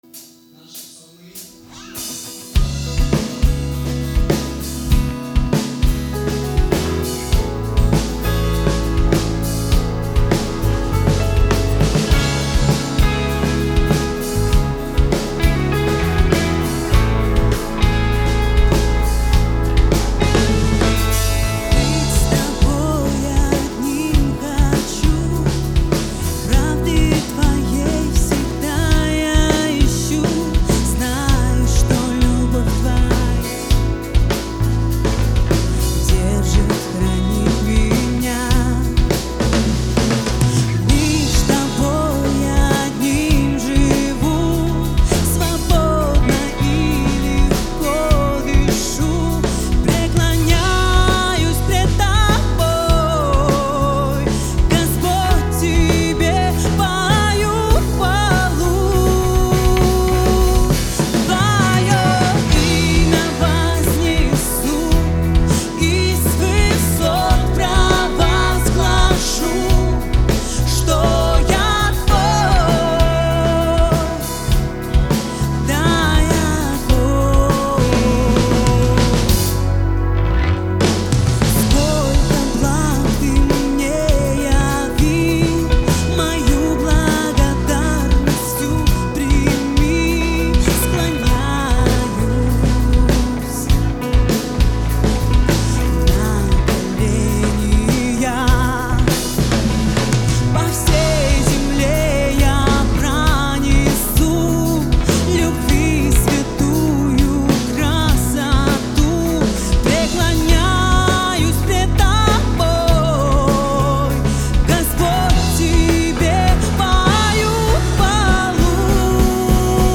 174 просмотра 67 прослушиваний 1 скачиваний BPM: 100